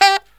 HIHITSAX01-L.wav